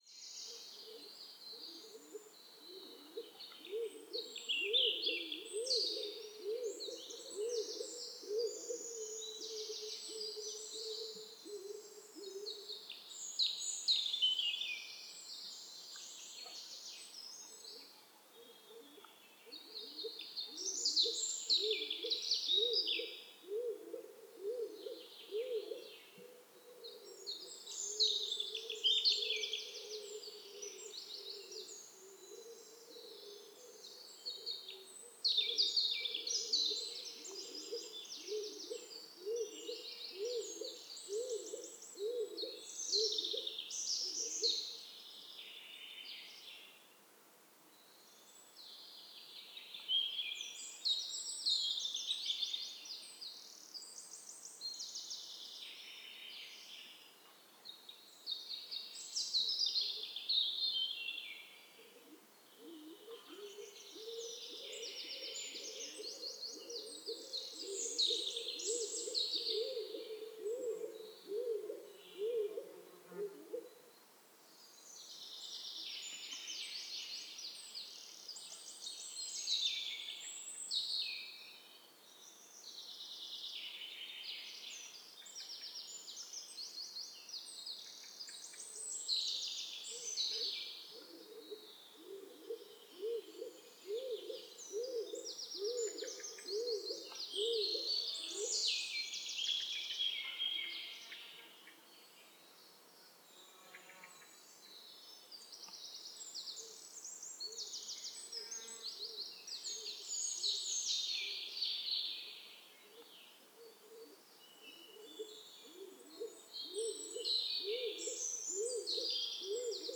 Pomba zura
Canto